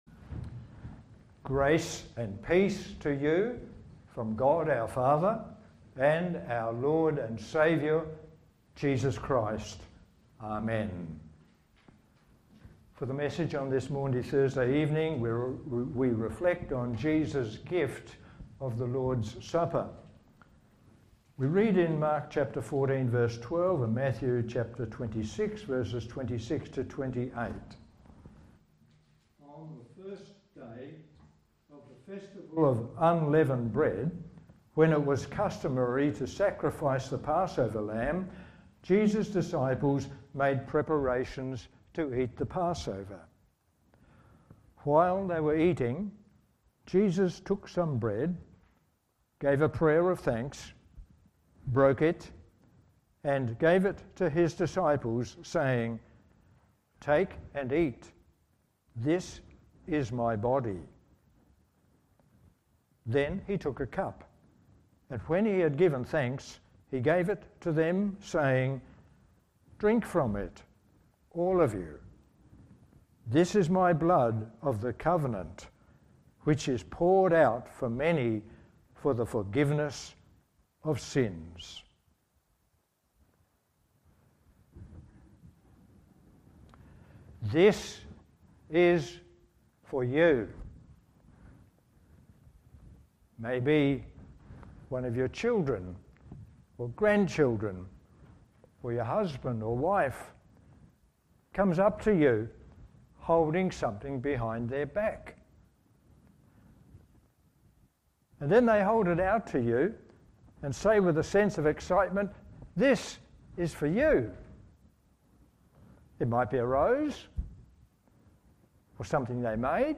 Sermons Online Audio Thursday 2 Apr Maundy Thursday